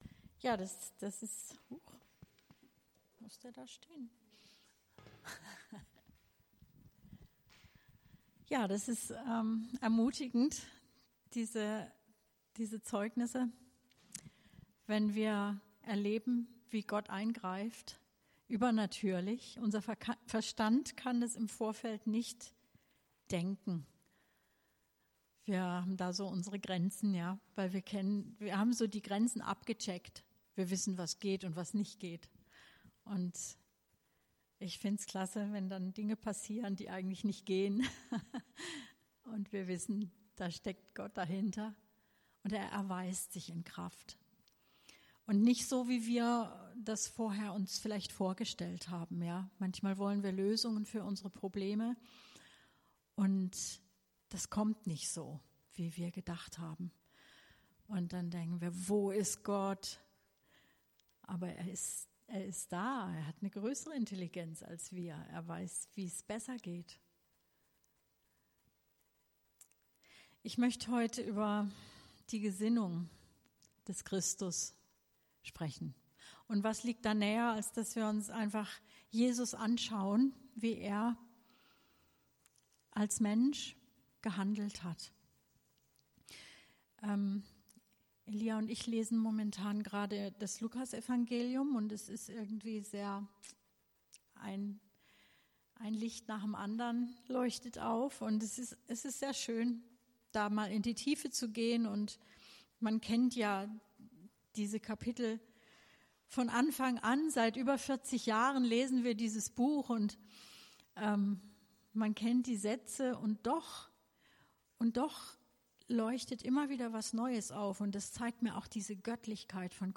Predigt 24.06.2018